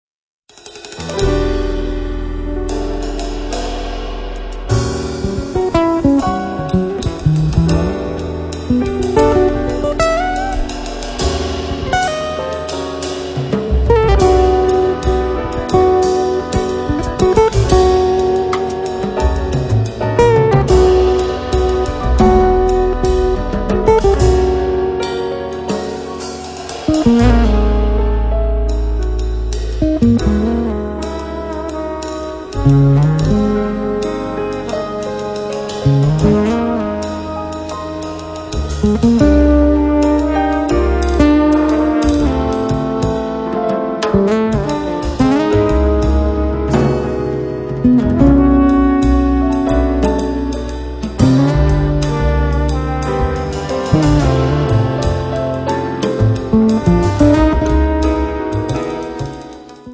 chitarra
contrabbasso